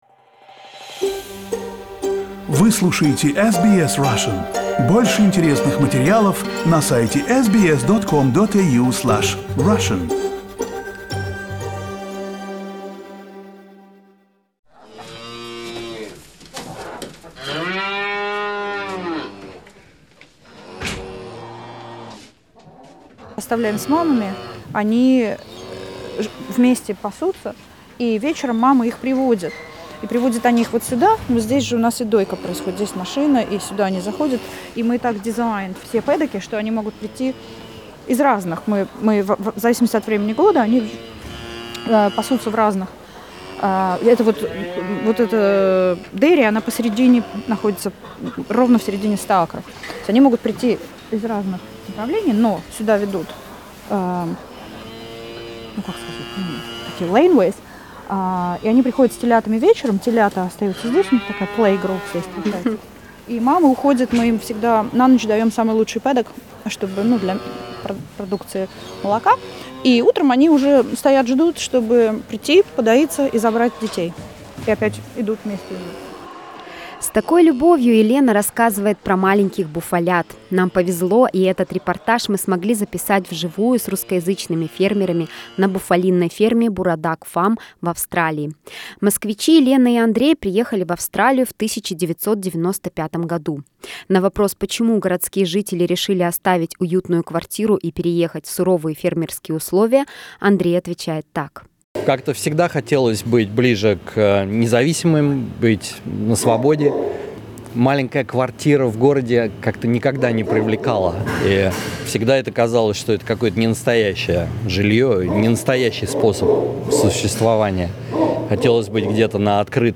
Предлагаем вам послушать репортаж с буйволиной фермы Burraduc Buffalo Farm, где живут русскоязычные фермеры, которые смогли создать самую лучшую итальянскую Моцареллу в Австралии.